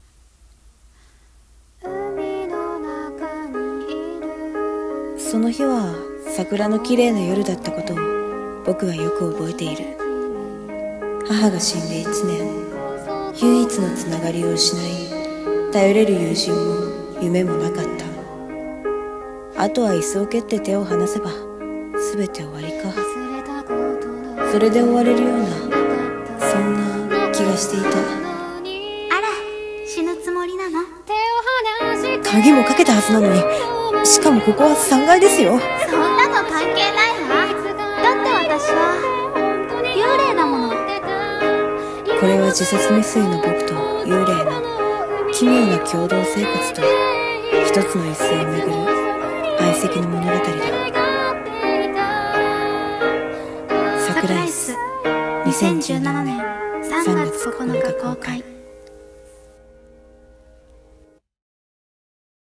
偽映画予告CM「桜椅子」